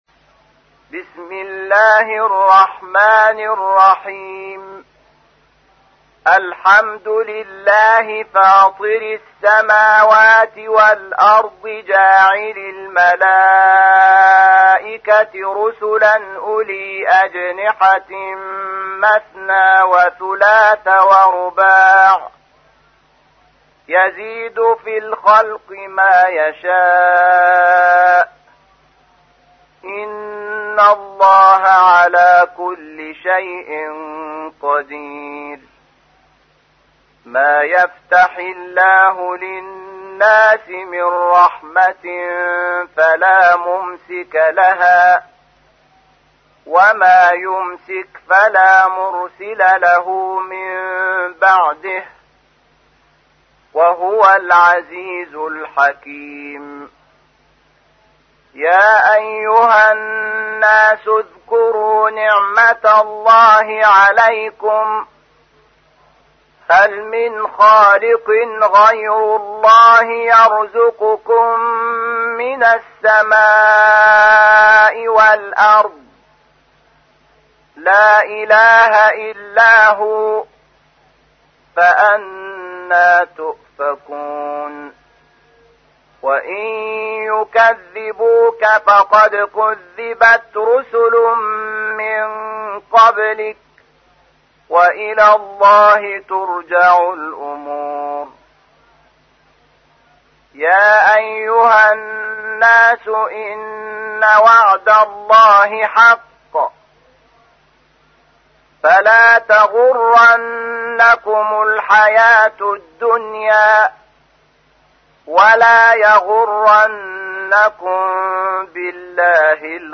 تحميل : 35. سورة فاطر / القارئ شحات محمد انور / القرآن الكريم / موقع يا حسين